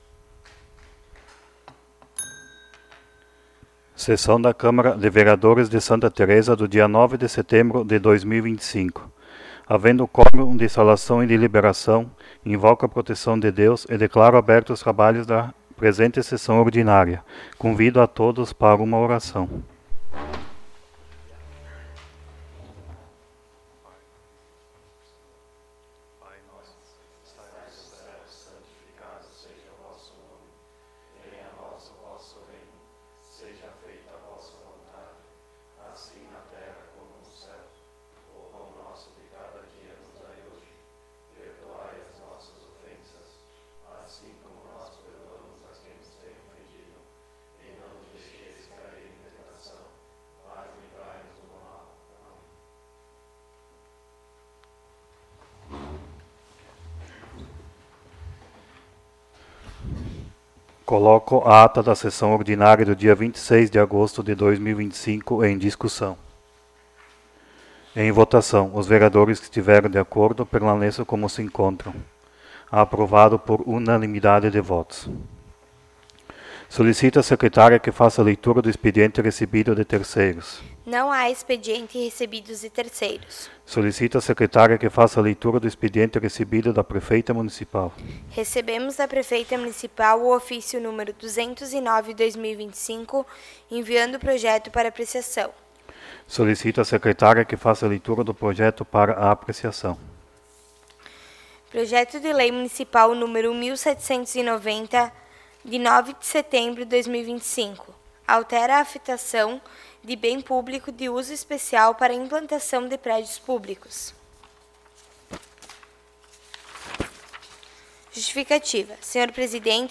15° Sessão Ordinária de 2025
Áudio da Sessão